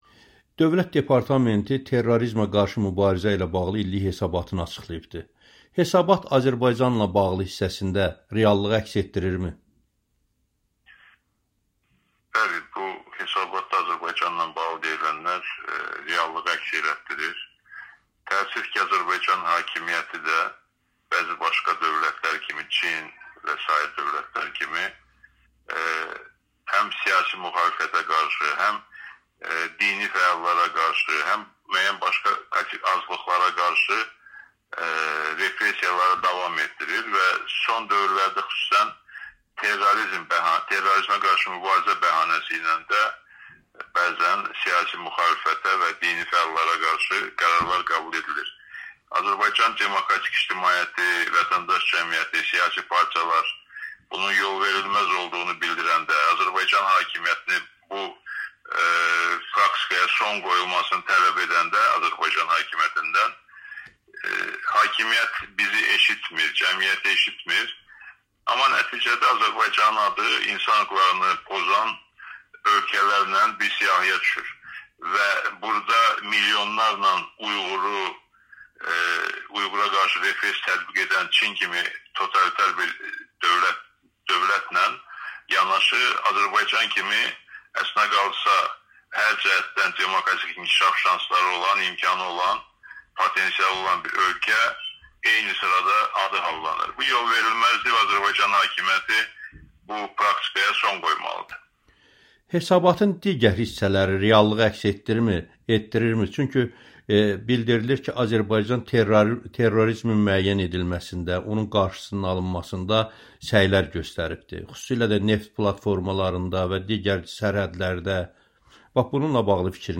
Milli Strateji Düşüncə Mərkəzinin rəhbəri İsa Qəmbər Amerikannın Səsinə müsahibəsində bildirib ki, Dövlət Departamentinin hesabatının Azərbaycanla bağlı hissəsi reallığı əks etdirir.